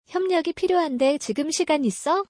「협력」は「ㄹ（リウル）の鼻音化」により、「ヒョニョ」と発音します。
kyoryokugahitsuyonandakedoimajikanaru1.mp3